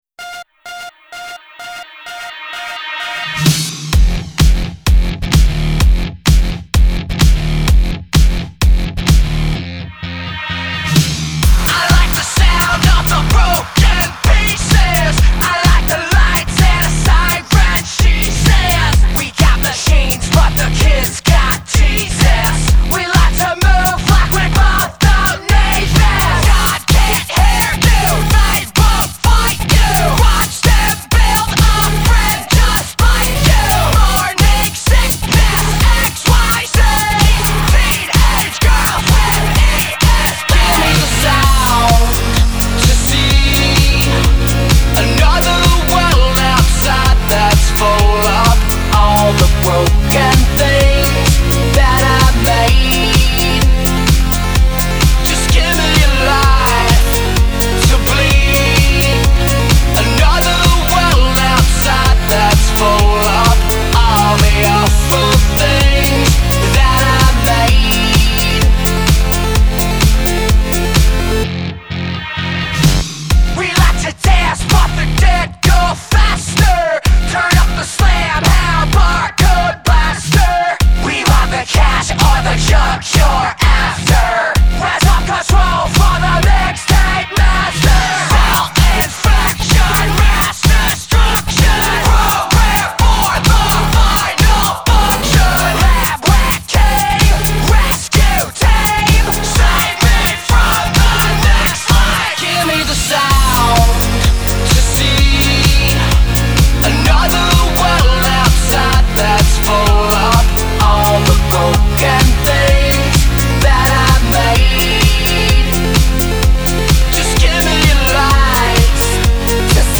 Electronic Dance Music